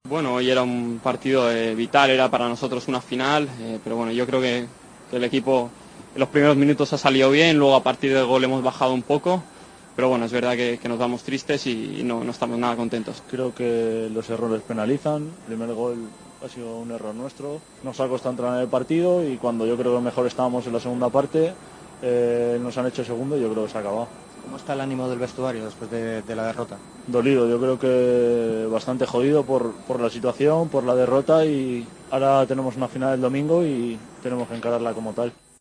Redacción digital Madrid - Publicado el 25 nov 2015, 00:11 - Actualizado 19 mar 2023, 04:27 1 min lectura Descargar Facebook Twitter Whatsapp Telegram Enviar por email Copiar enlace El canterano y el capitán del Valencia hablaron después de caer ante el Zenit.